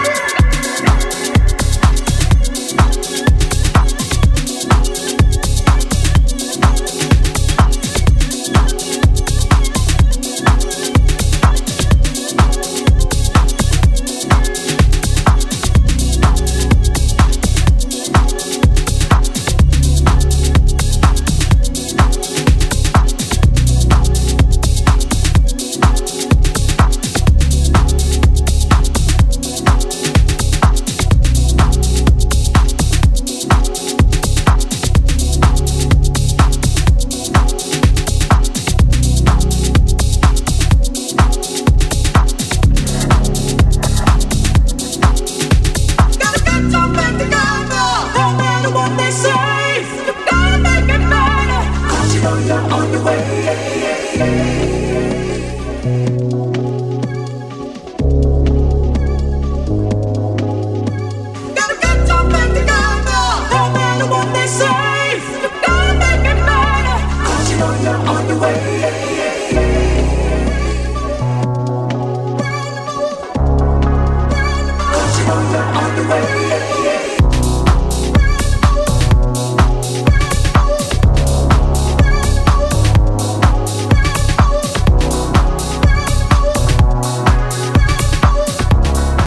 Warm bass driven house with nice flowing chords.
House Techno Acid